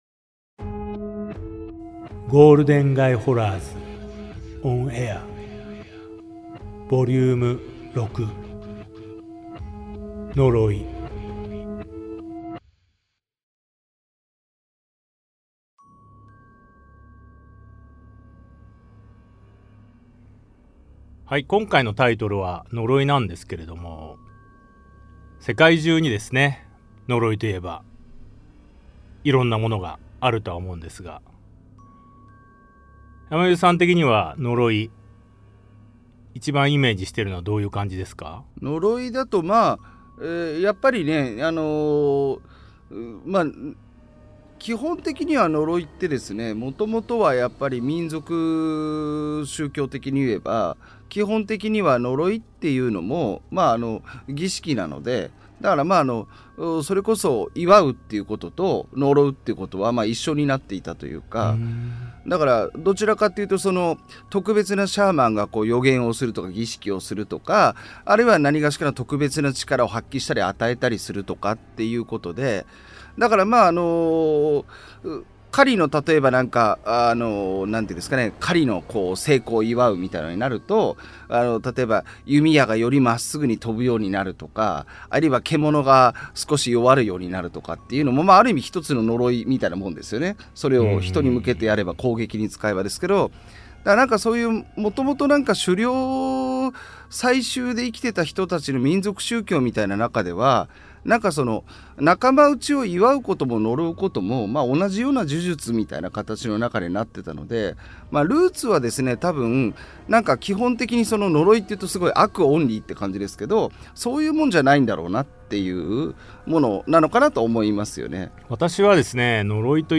対談形式のホラー番組